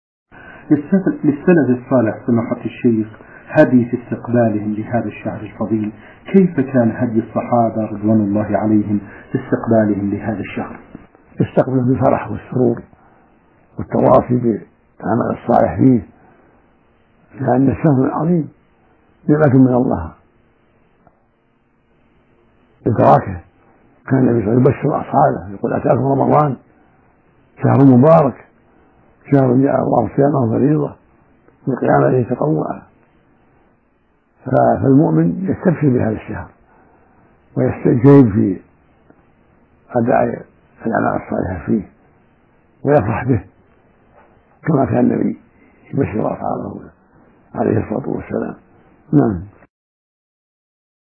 من مواعظ أهل العلم